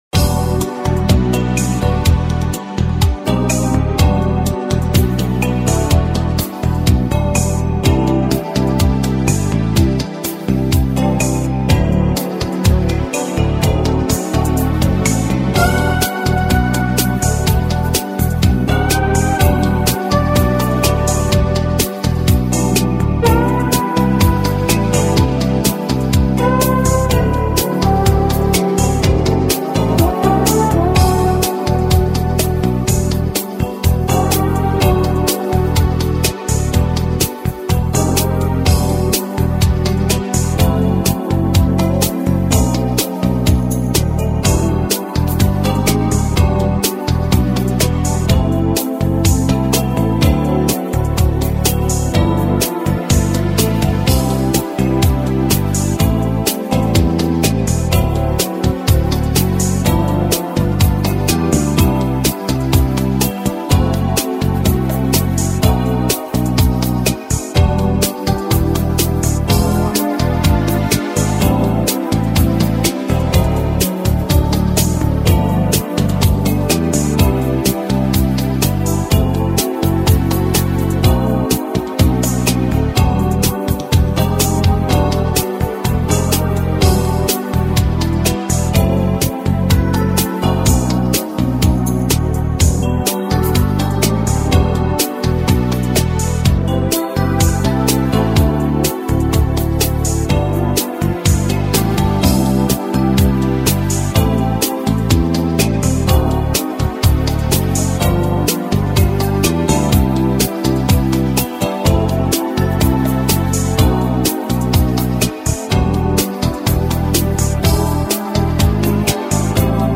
Минус Инструментал